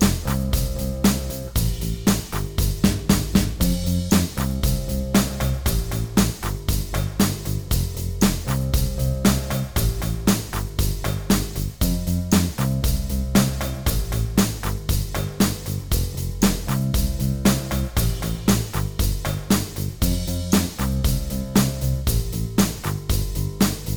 Minus All Guitars Pop (1980s) 4:25 Buy £1.50